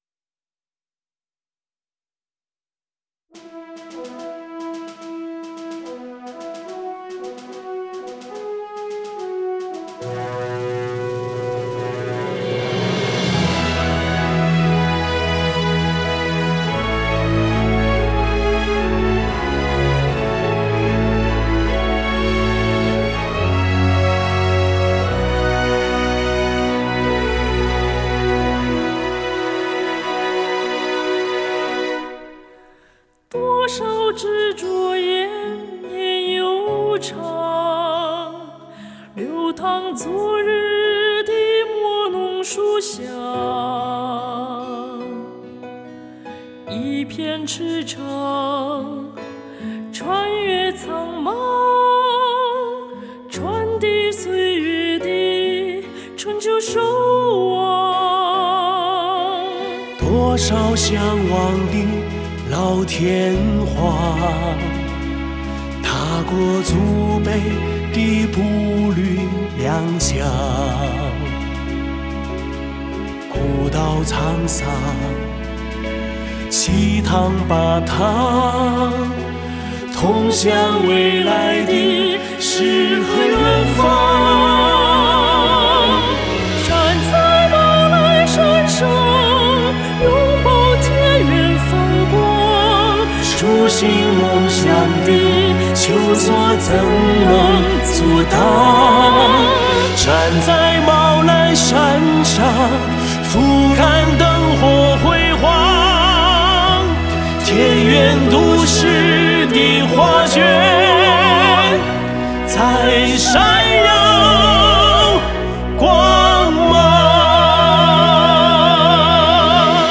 女歌手
男歌手